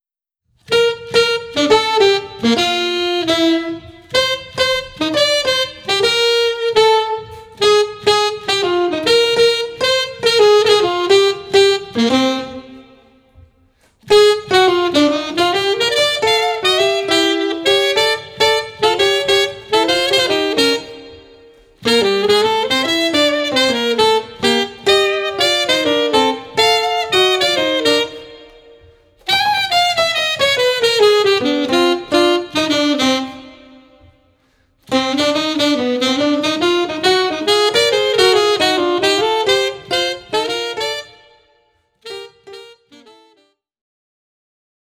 スリリングながらも暖かいJAZZ愛に溢れるDuo作品！
Alto Sax
Piano